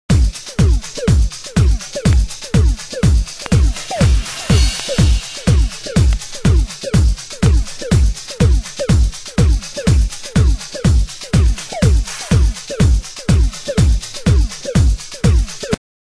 、A2アウトロにノイズ 有/.少しチリパチノイズ有